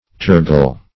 Search Result for " tergal" : The Collaborative International Dictionary of English v.0.48: Tergal \Ter"gal\, a. [L. tergum the back.]